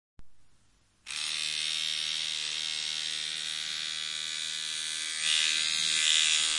电动牙刷的声音